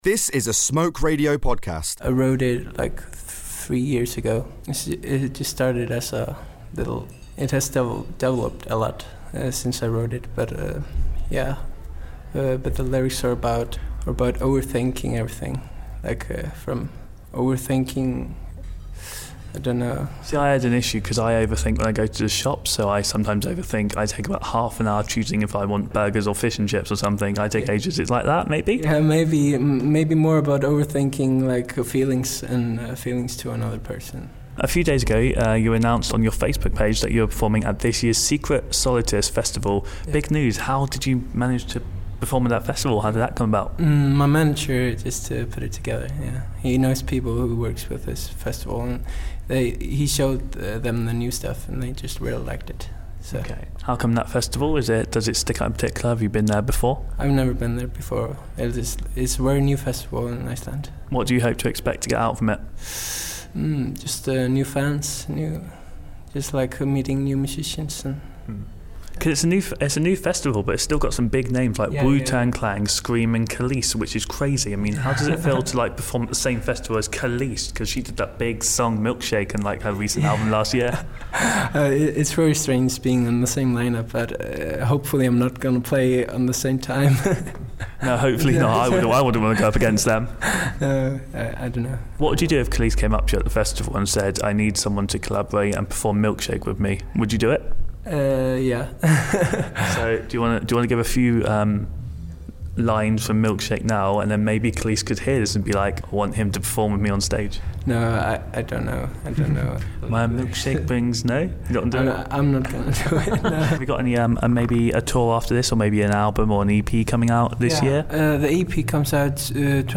chat
before his set at the Trellis Records 1st Anniversary celebrations.